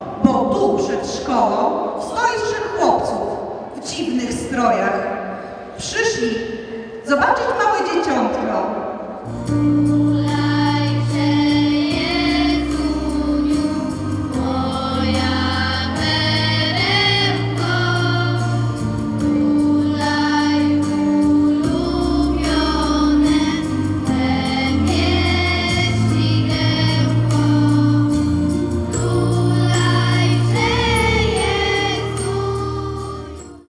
Dzieci odgrywają rolę Matki Boskiej i Józefa, Trzech Króli i aniołków, a wszystko przy śpiewie kolęd.
Tak było dziś w Szkole Podstawowej nr 5 im. Marii Konopnickiej w Ełku.